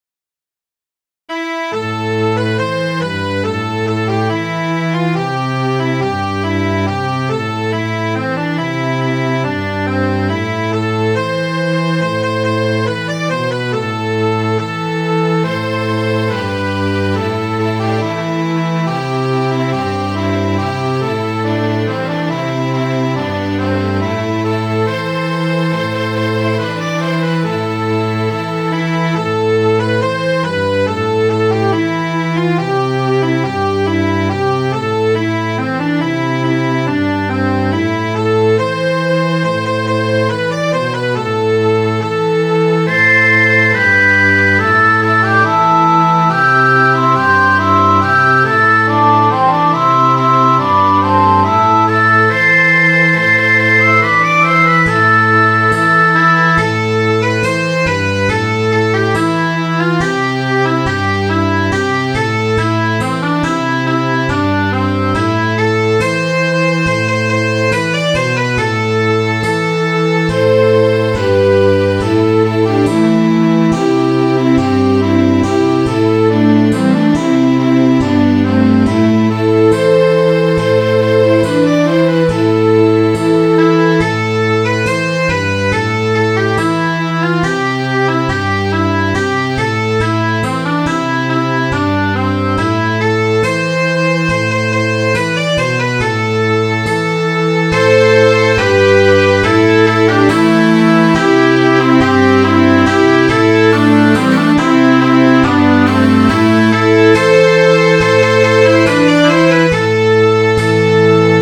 Midi File, Lyrics and Information to Johnny's Gone For a Soldier